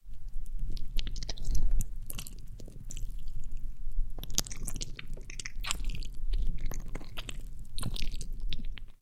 Извлекаем сердцевину помидора